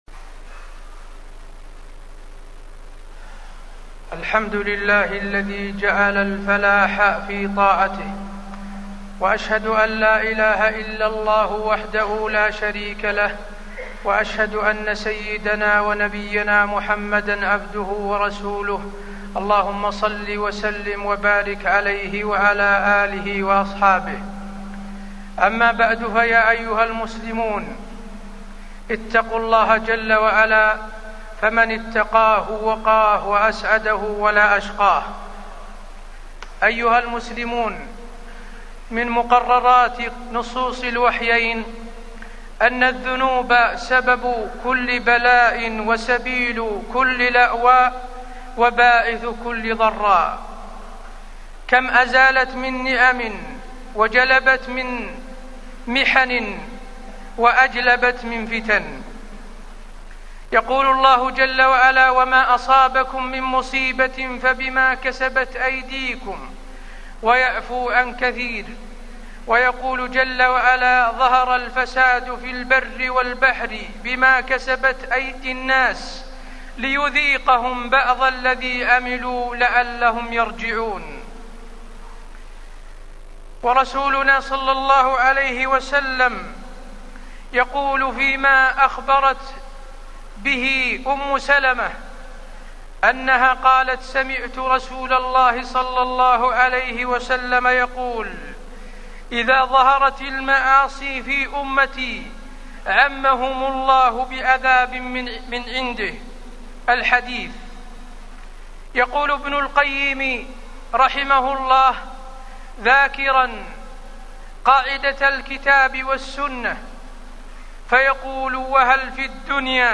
تاريخ النشر ١١ جمادى الأولى ١٤٢٩ هـ المكان: المسجد النبوي الشيخ: فضيلة الشيخ د. حسين بن عبدالعزيز آل الشيخ فضيلة الشيخ د. حسين بن عبدالعزيز آل الشيخ الإيمان سبب الأمن